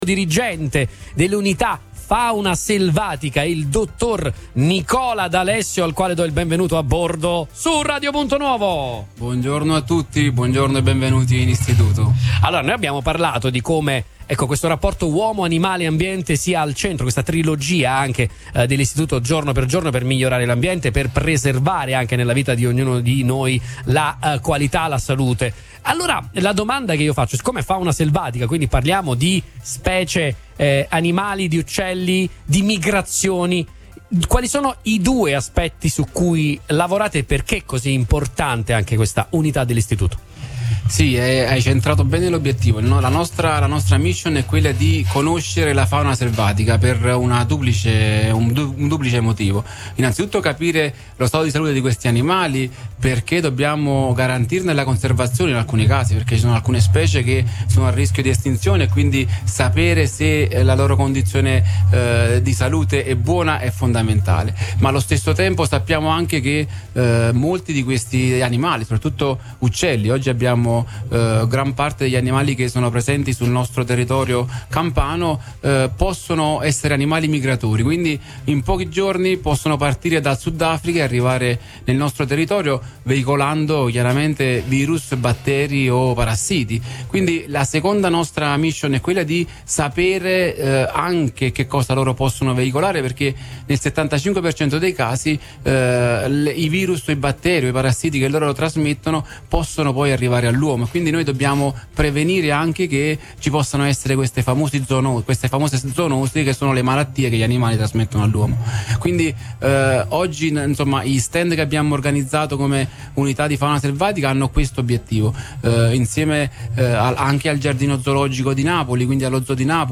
Fattorie Aperte, la sedicesima edizione è un grande successo
DI SEGUITO IL PODCAST DELLE INTERVISTE